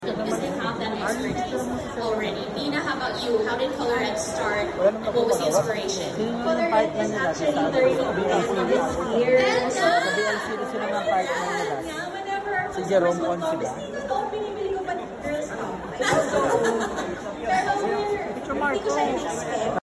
during the Beauty Con 2025 held at Smx Convention Center, Pasay City